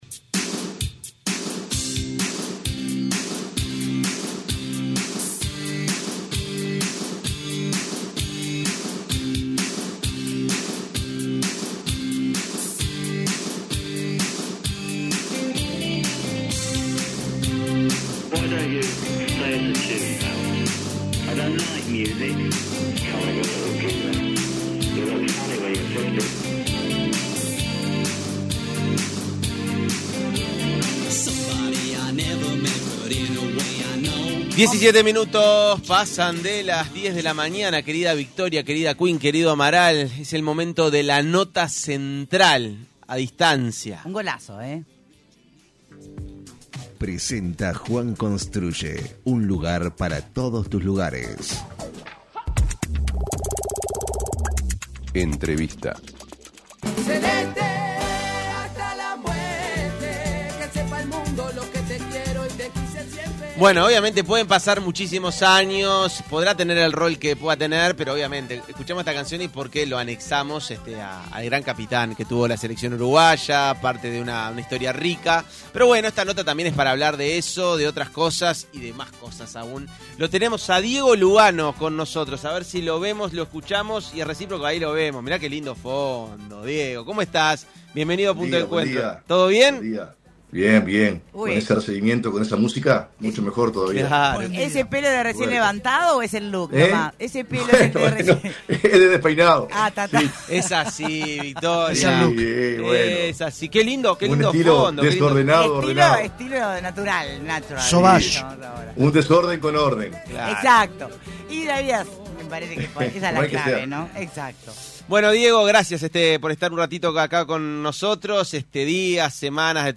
AUDIO En entrevista con Punto de Encuentro, el excapitán de la selección uruguaya de fútbol, Diego Lugano habló sobre la batalla por los derechos de televisión que la AUF logró licitar después de 26 años en manos de Tenfield.